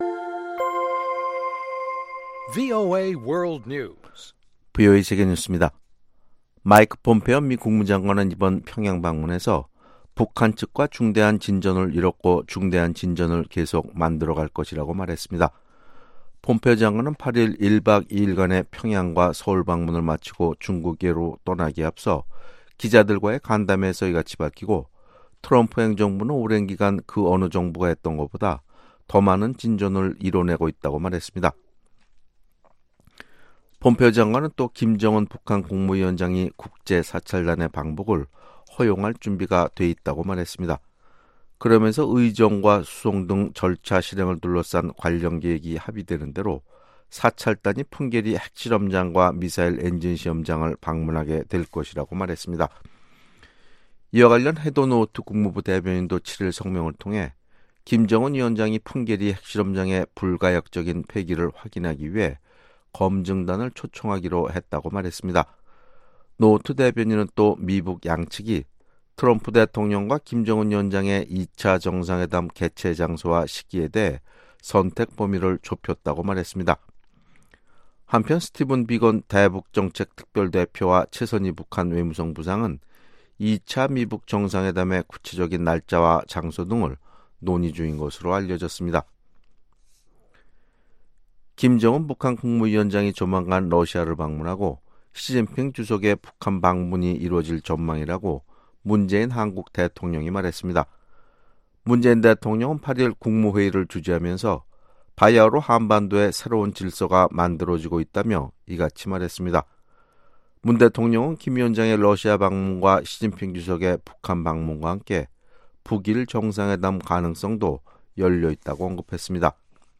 VOA 한국어 아침 뉴스 프로그램 '워싱턴 뉴스 광장' 2018년 10월 9일 방송입니다. 마이크 폼페오 미 국무장관은 이번 방북을 통해 비핵화의 중대한 진전을 이뤘다고 밝히면서, 핵 사찰단이 곧 북한을 방문하게 될 것이라고 말했습니다. 웨인 에어 유엔 군 사령부 부사령관은 유엔 군 사령부 해체와 관련해 언젠가는 이뤄지겠지만 올바른 시기에만 가능한 사안이라고 밝혔습니다.